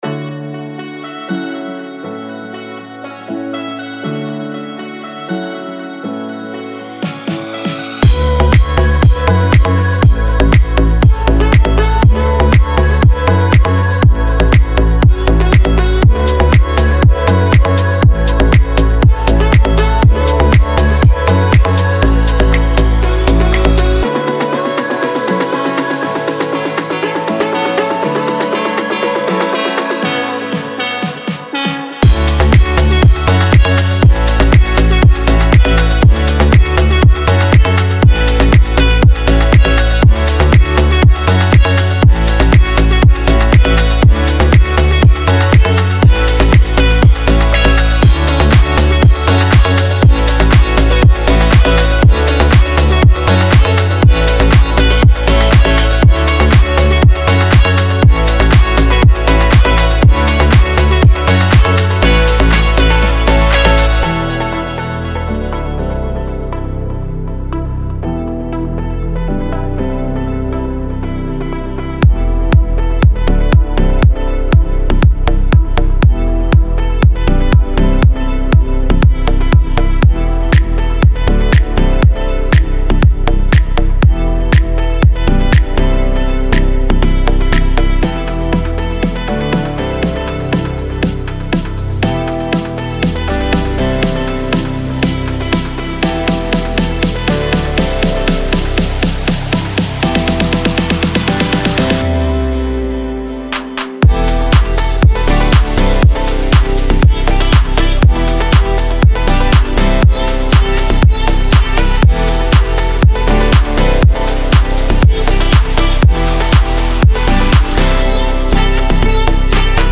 Pop Tropical House
5 x Top Quality Tropical Pop Construction Kits.
All Kits BPM & Key-Labelled. (Kits BPM 120 – 123).